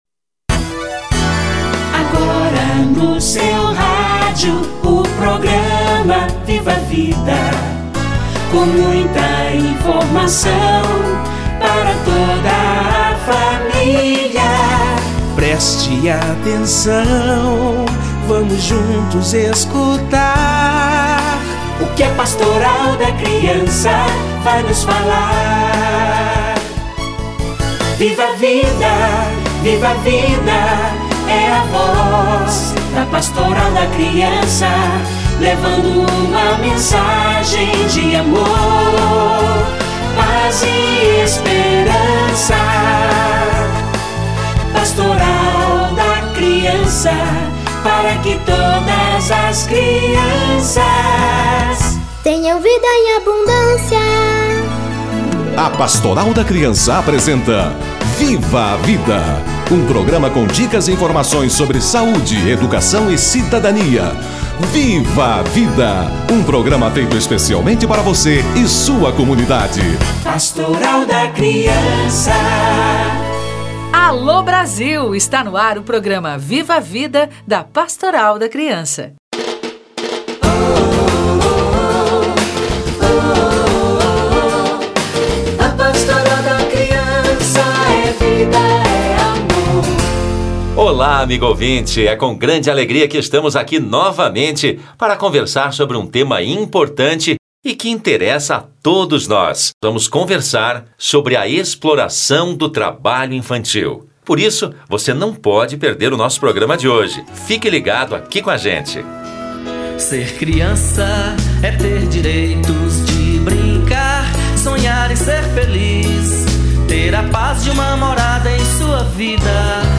Trabalho infantil - Entrevista